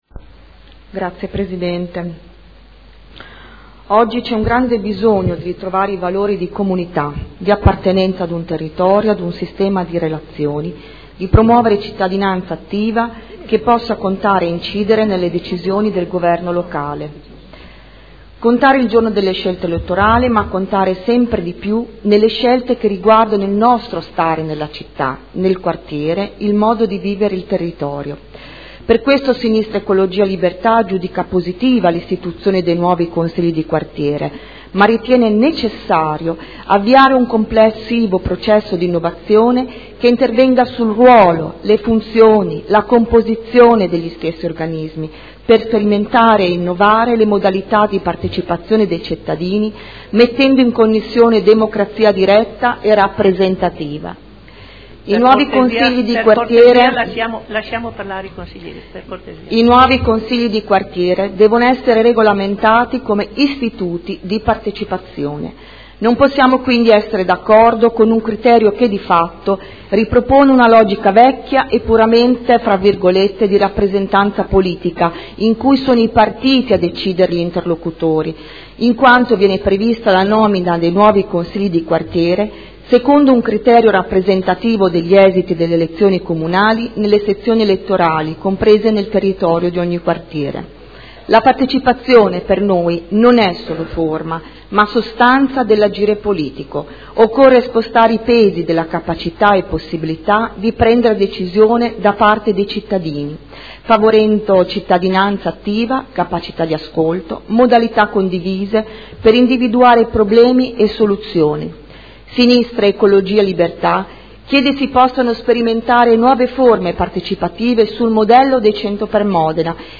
Ingrid Caporioni — Sito Audio Consiglio Comunale